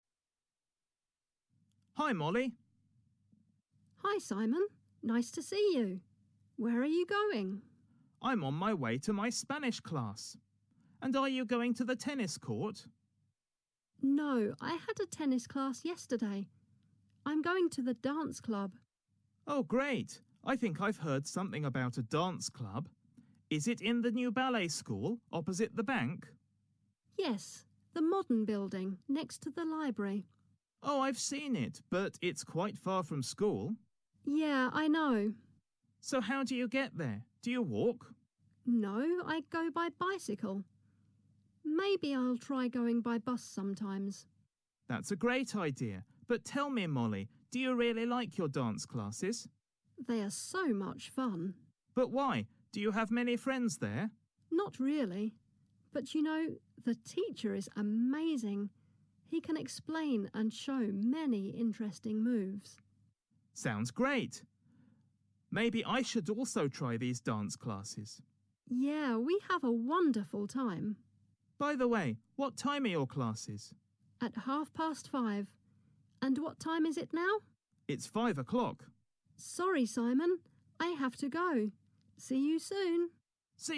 Вы услышите диалог.